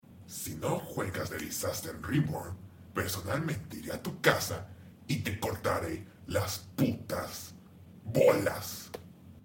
Some random Sonic.EXE voice over sound effects free download
Some random Sonic.EXE voice over one of my irl friends did..